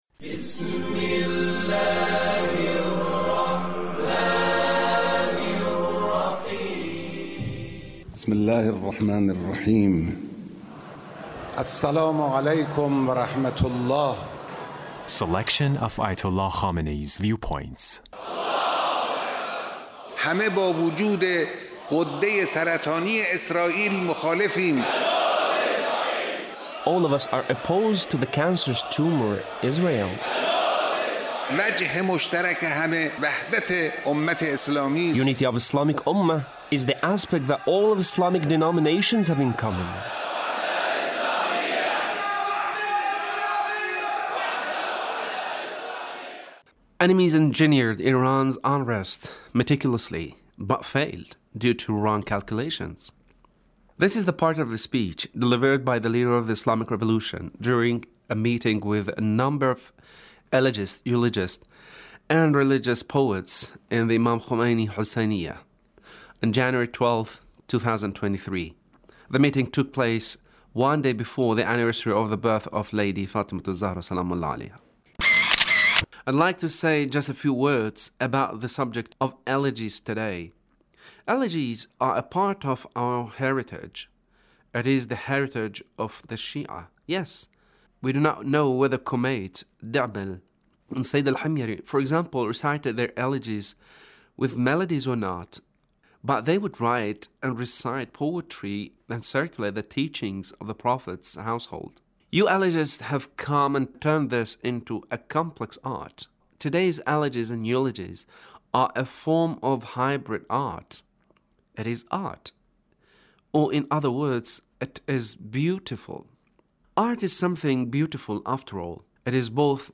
Leader's Speech meeting with Eulogists